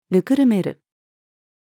温める-to-cool-(water)-down-female.mp3